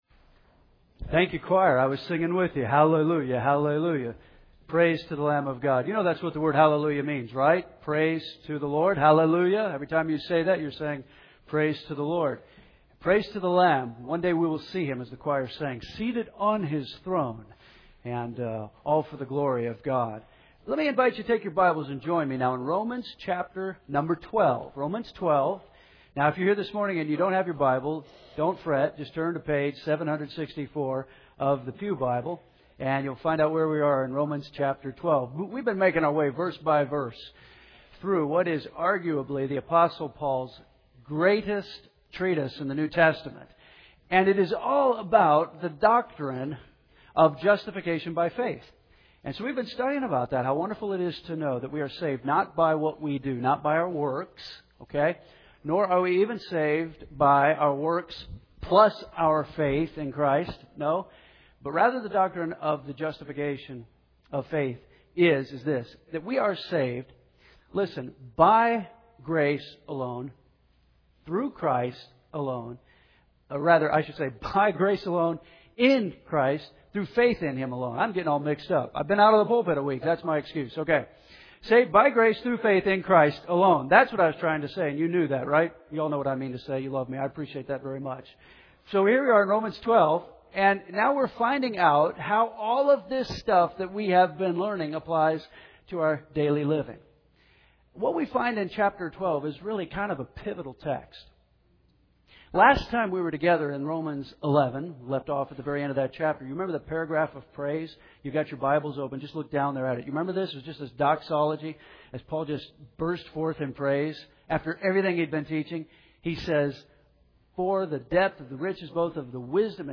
First Baptist Henderson, KY